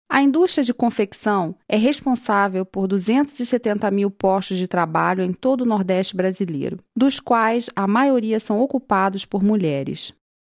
sprecherdemos
brasilianisch-port. w_01